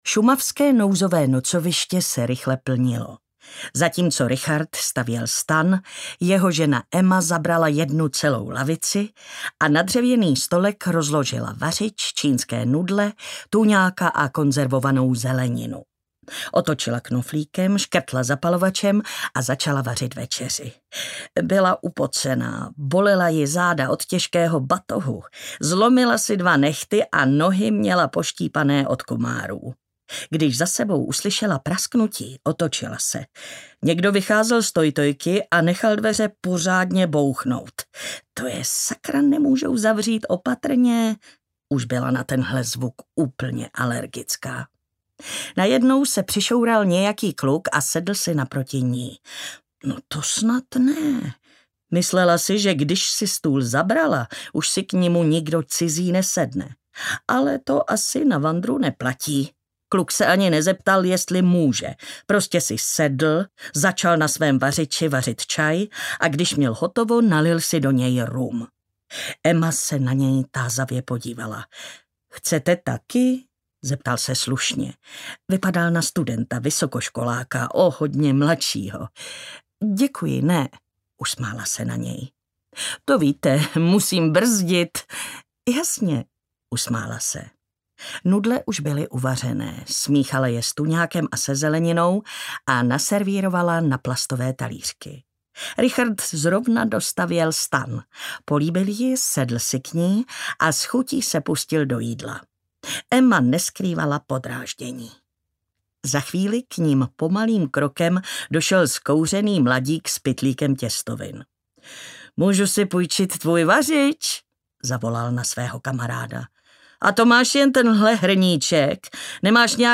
Smrková Tišina audiokniha
Ukázka z knihy
• InterpretMartina Hudečková